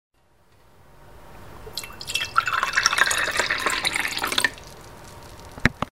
水をそそぐ
まずは、僕がレコーダーを買って初めて録音した音です。
でも、これをグラスに水が注がれる風景を想像しながら聴いて下さい。
Water.mp3